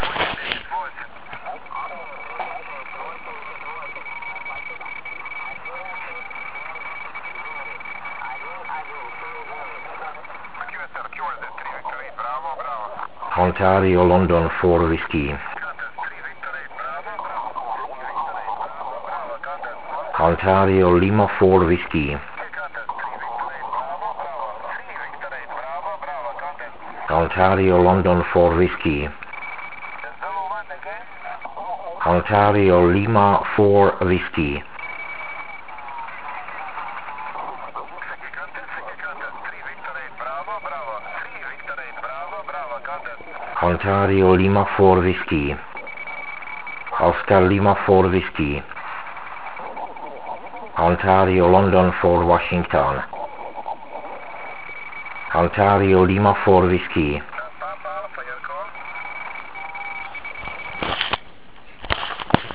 (V dalším jsou záznamy z tohoto závodu)